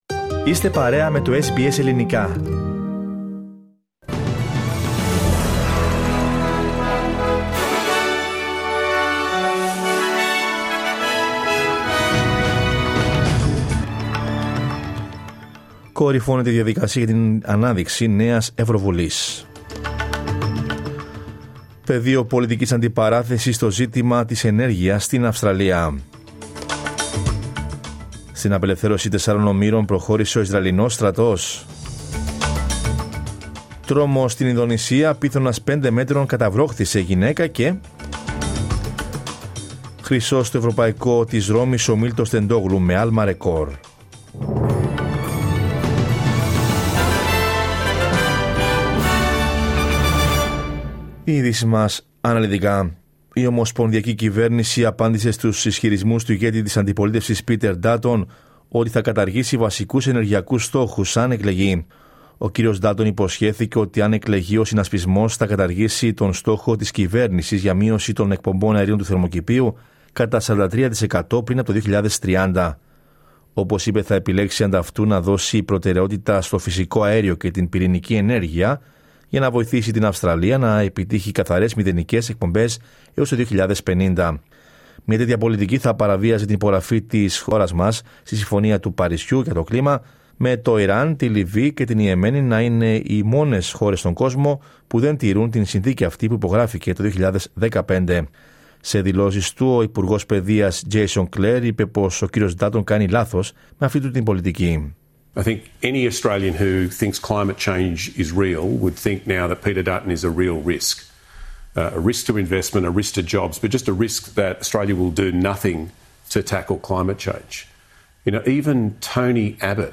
Δελτίο Ειδήσεων Κυριακή 9 Ιουνίου 2024